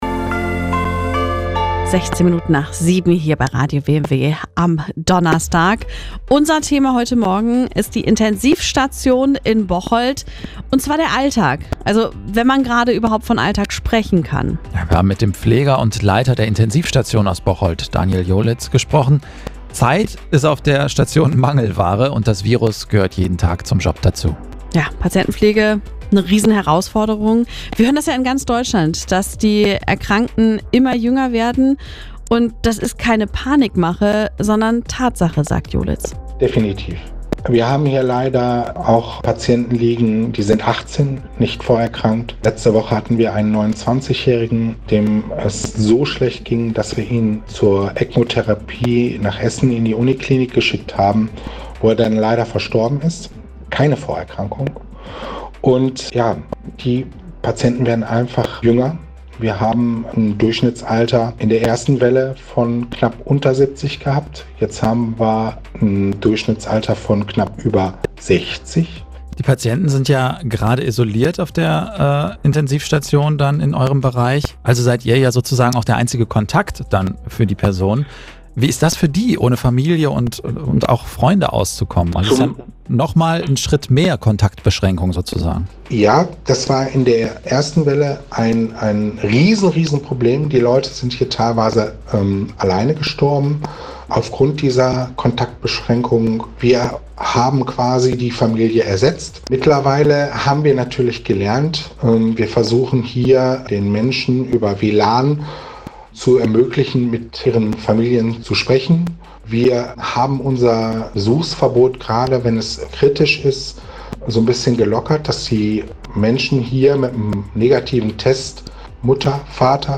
Der RADIO WMW Directors Cut – das ungefilterte Interview.